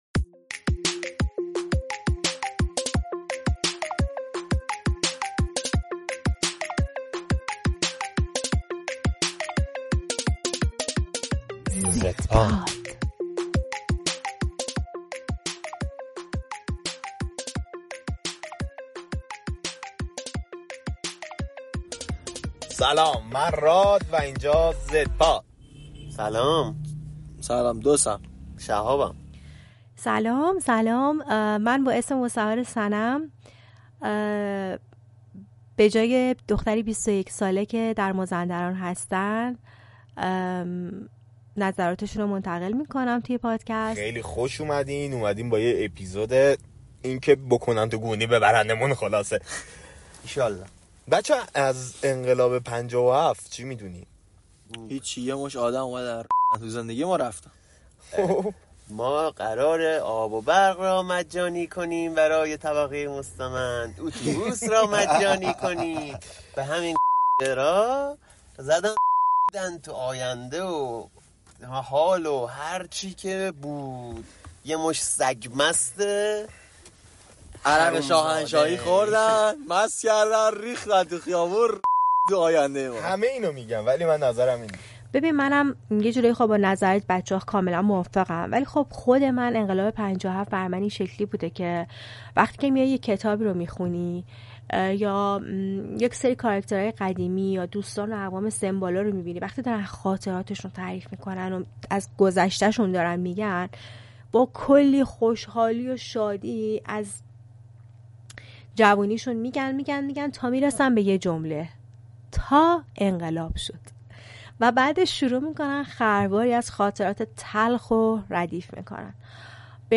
«زدپاد» پادکستی است که توسط گروهی از جوانان ایرانی معروف به نسل زد داخل کشور تهیه می‌شود و شامل گفتگو گروهی از جوانان درباره موضوع‌های مختلف روز است.
این قسمت «زدپاد» گفتگوی چند نفر از جوانان ایرانی معروف به نسل زد درباره انقلاب ۵۷ است.